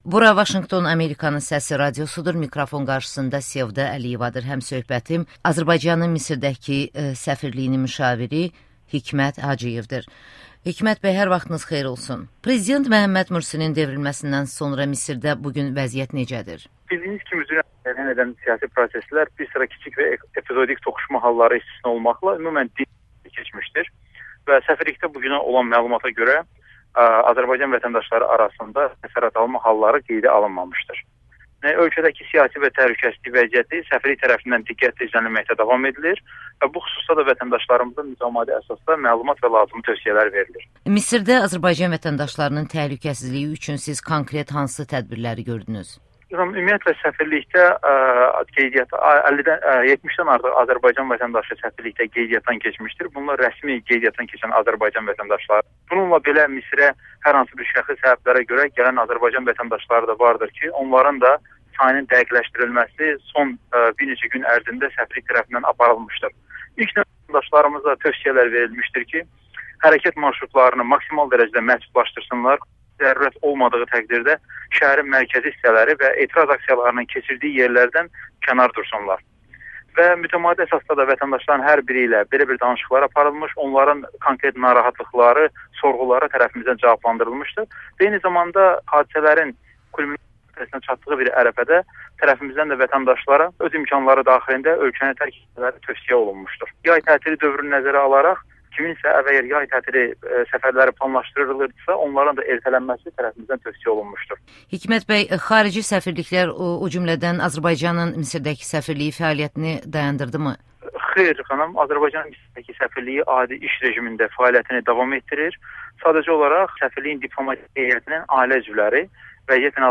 Hikmət Hacıyevlə müsahibə